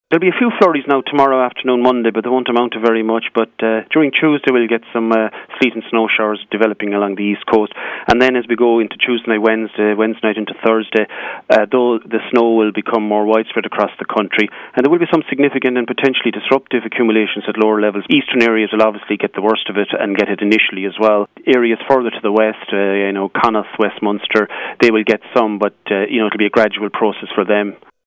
Forecaster